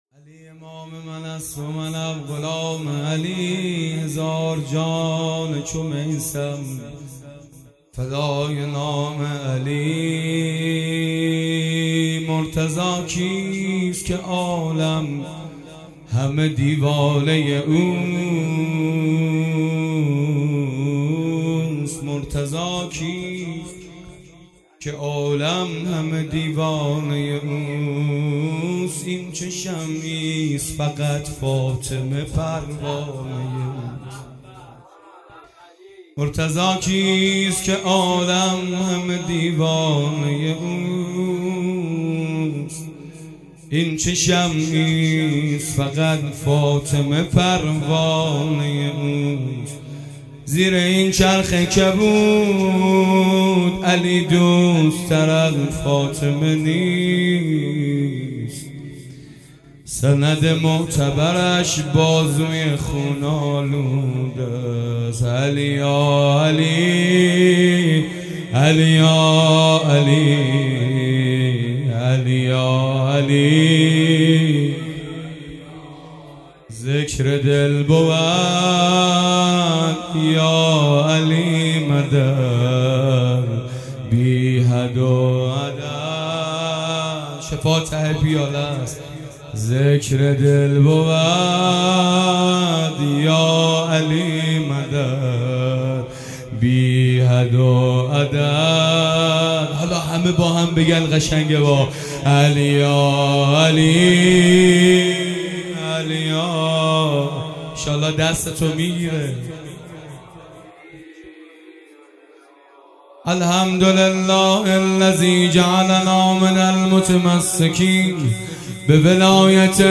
مدح پایانی
جشن ولادت امیرالمومنین (ع) 13 بهمن 1401